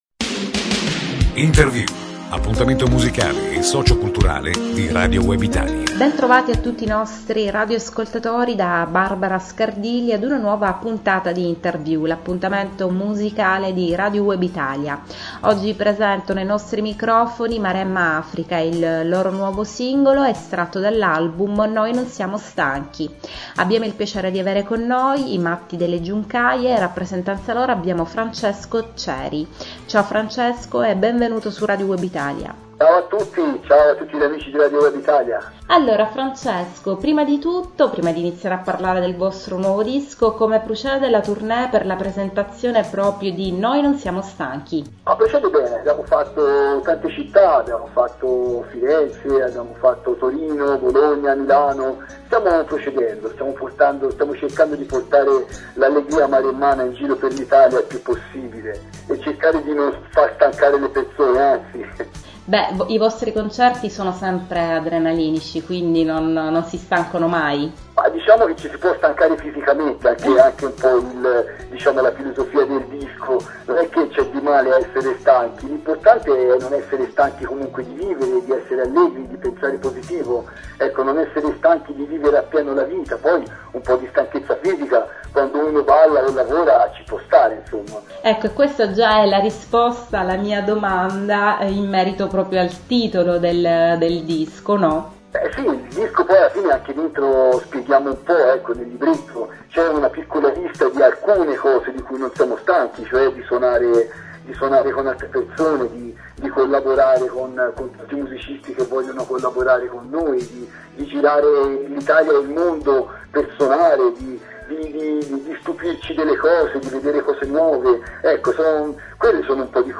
I Matti delle Giuncaie e il nuovo album ‘Noi non siamo stanchi’, l’intervista - Radio Web Italia